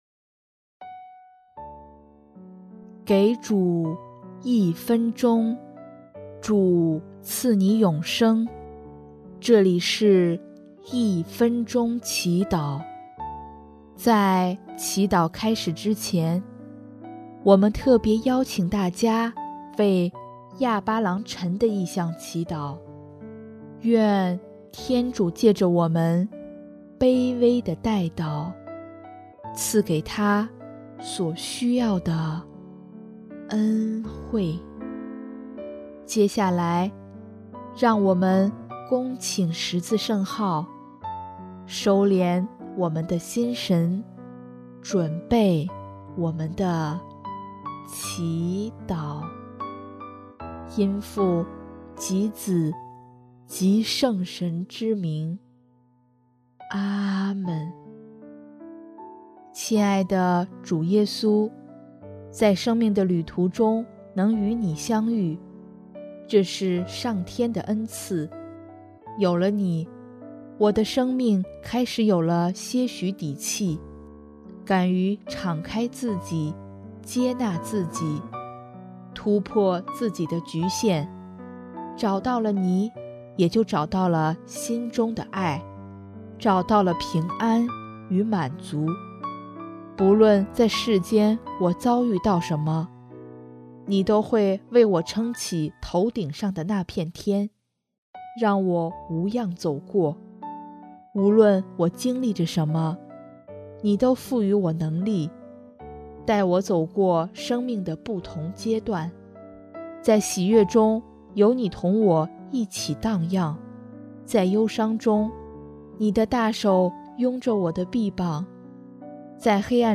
音乐： 第四届华语圣歌大赛参赛歌曲《感谢主恩歌》